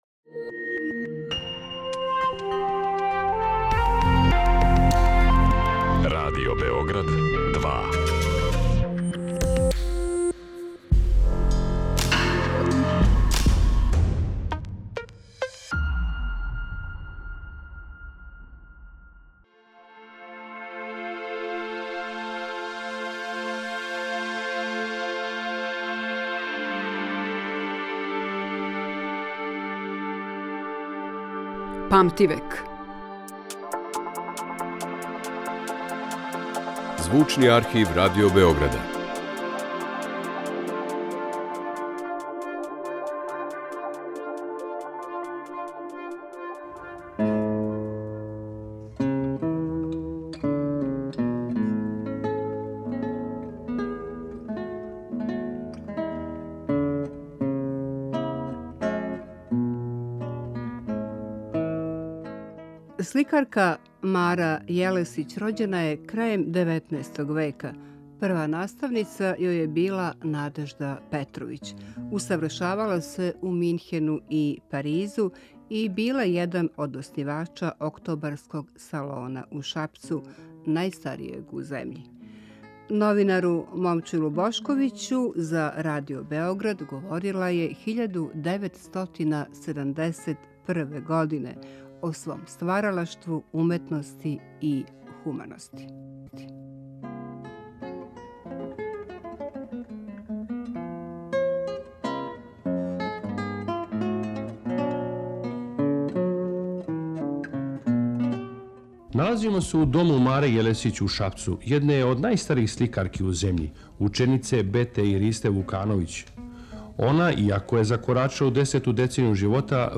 Емисија која ће покушати да афирмише богатство Звучног архива Радио Београда, у коме се чувају занимљиви, ексклузивни снимци стварани током целог једног века, колико траје историја нашег радија.